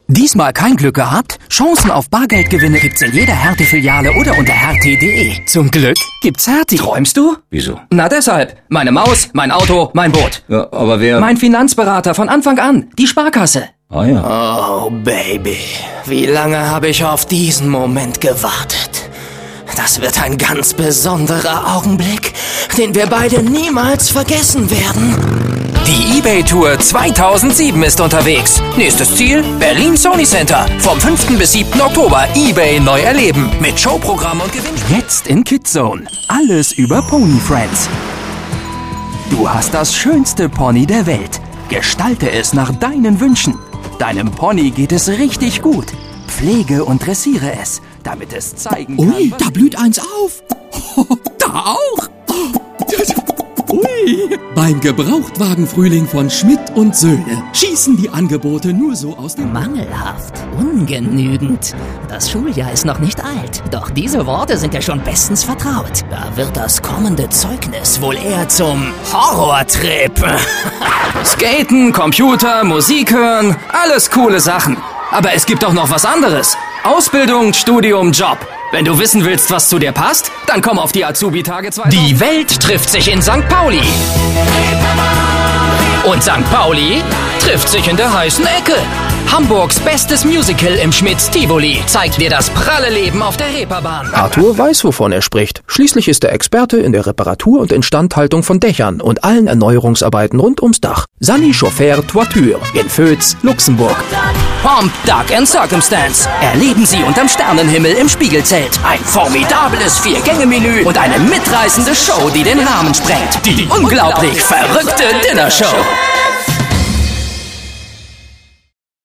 Als Werbesprecher profitieren Sie von meiner großen Bandbreite, egal ob fetzig dynamisch, oder einfühlsam natürlich.
Junge Stimme, Sprecher für: Werbung, Audio Ads, Dokumentation, E-Learning, Zeichentrick, Jingles, Lieder, Synchron, etc.
Sprechprobe: Werbung (Muttersprache):